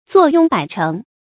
坐擁百城 注音： ㄗㄨㄛˋ ㄩㄥ ㄅㄞˇ ㄔㄥˊ 讀音讀法： 意思解釋： 有一萬卷書，勝似管理一百座城的大官。